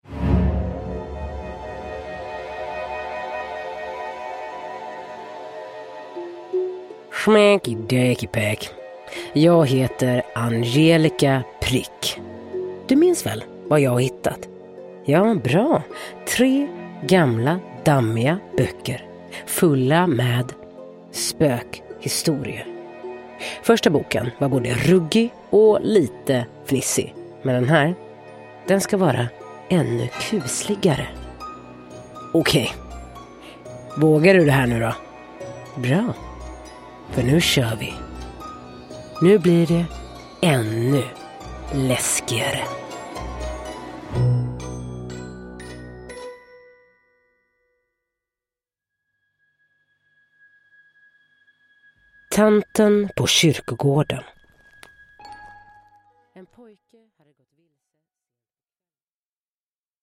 – Ljudbok